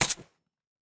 sounds / weapons / _bolt / 556_3.ogg